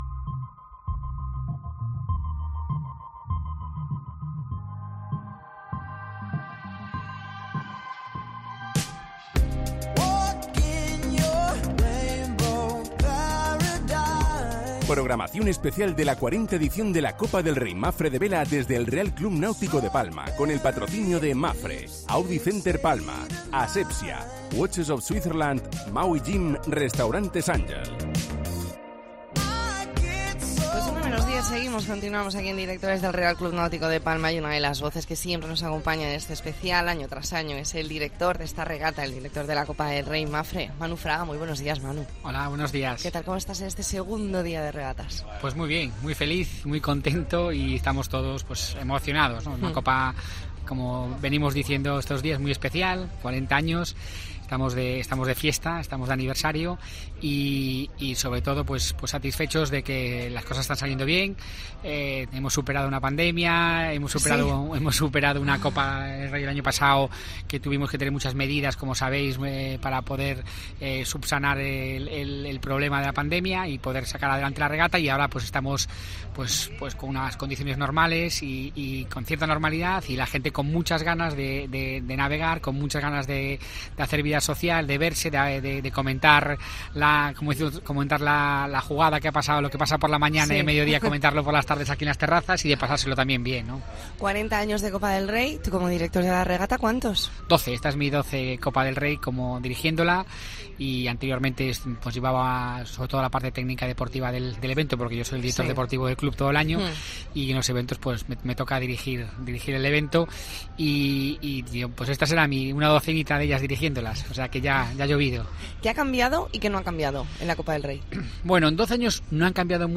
Especial La Mañana en COPE Más Mallorca desde el RCNP con motivo de la 40 Copa del Rey Mapfre